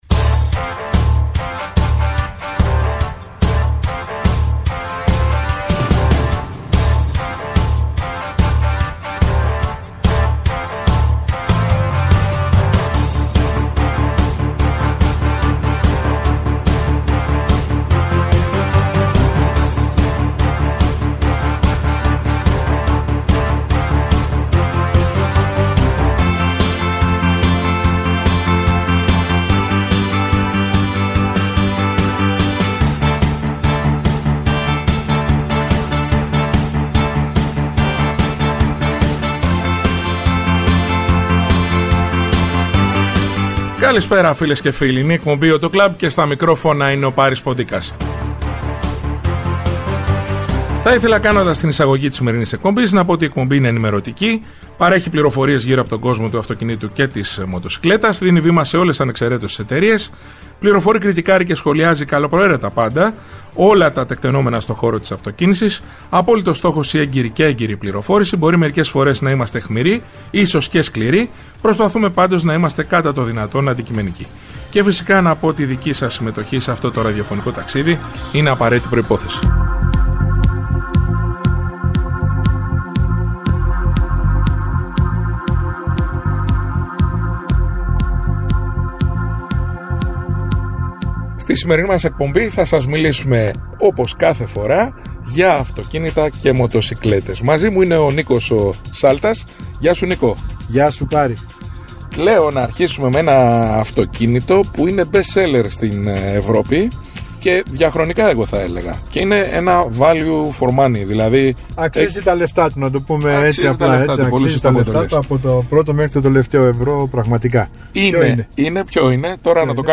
Η εκπομπή «AUTO CLUB» είναι ενημερωτική, παρέχει πληροφορίες γύρω από τον κόσμο του αυτοκινήτου και της μοτοσικλέτας, δίνει βήμα σε ολες ανεξεραίτως τις εταιρείες, φιλοξενεί στο στούντιο ή τηλεφωνικά στελέχη της αγοράς, δημοσιογράφους αλλά και ανθρώπους του χώρου. Κριτικάρει και σχολιάζει καλοπροαίρετα πάντα όλα τα τεκτενόμενα στο χώρο της αυτοκίνησης, με απόλυτο στόχο την έγκαιρη και έγκυρη πληροφόρηση για τους ακροατές, με «όπλο» την καλή μουσική και το χιούμορ.